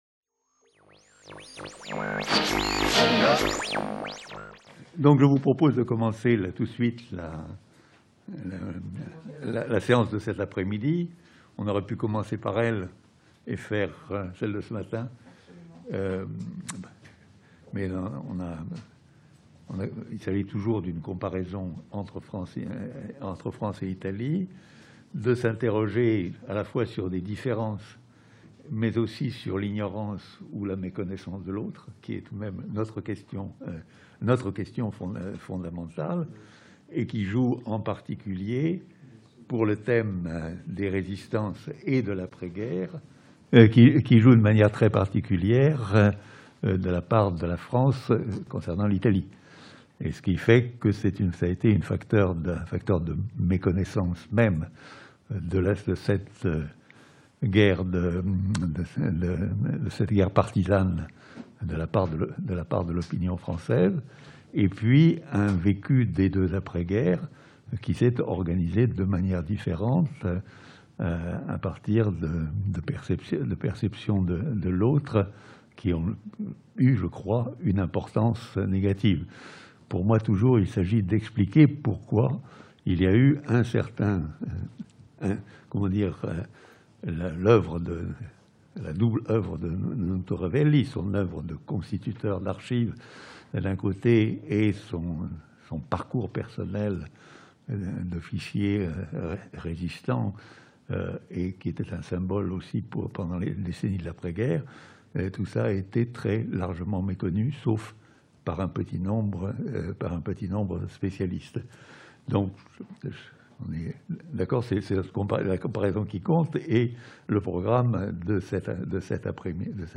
Intervention de Marco Revelli - Journée d'étude Nuto Revelli 4e partie | Canal U